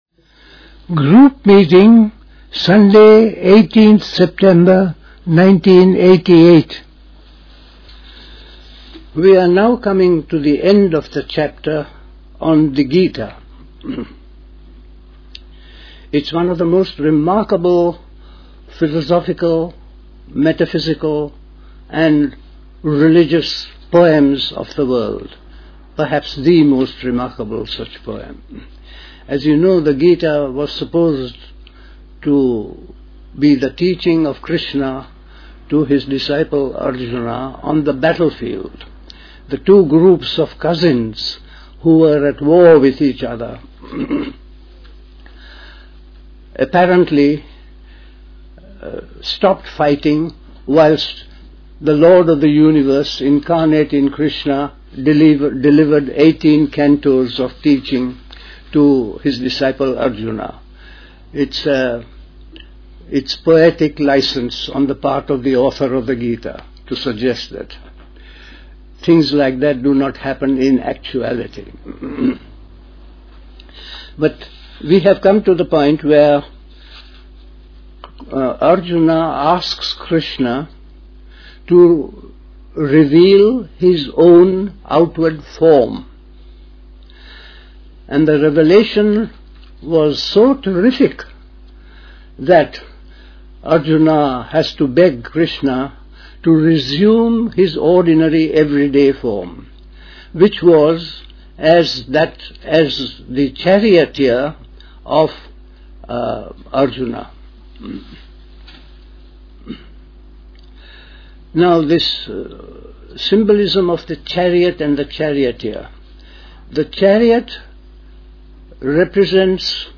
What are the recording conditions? at Dilkusha, Forest Hill, London on 18th September 1988